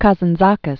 (käzən-zäkĭs, -zän-dzäkēs), Nikos 1883?-1957.